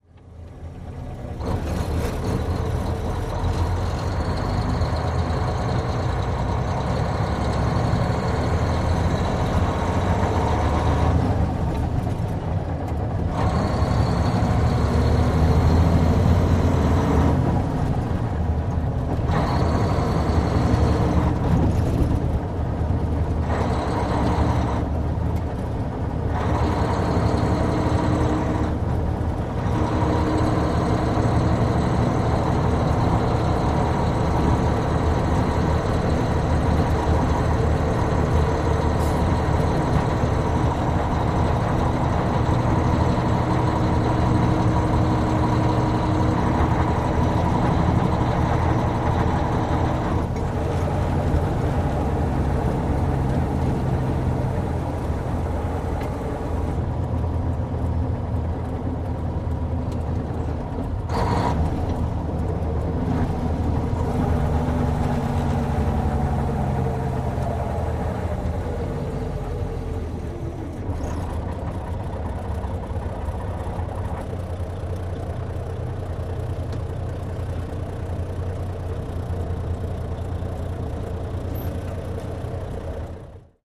tr_macktruck_driving_04_hpx
Mack truck interior point of view of driving and shifting gears. Vehicles, Truck Engine, Motor